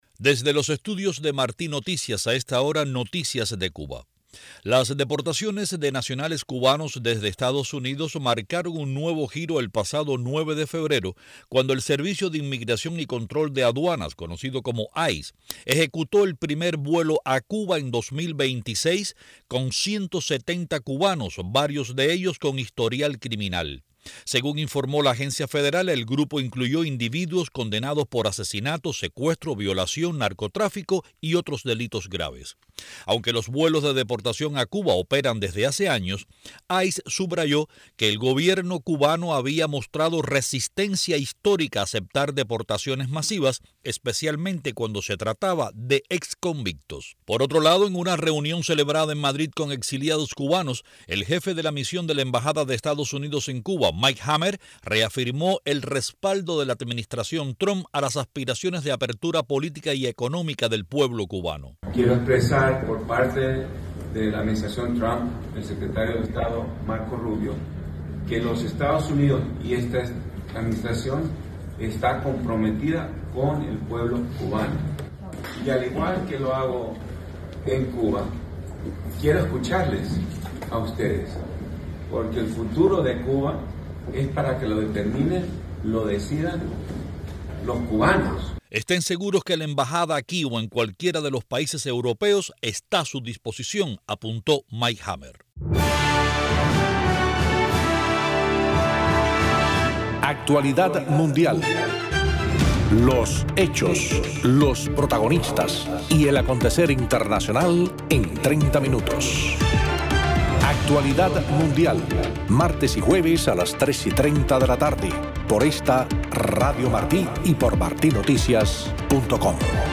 Desde los Estudios de Marti Noticias